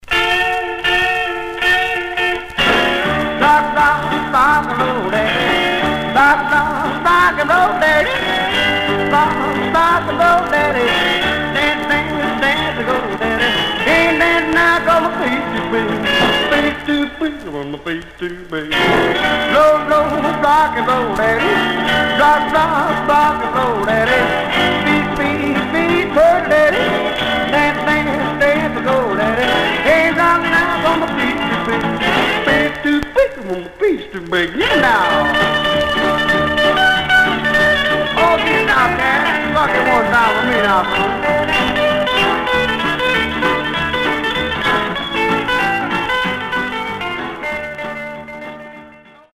Surface noise/wear
Mono
Rockabilly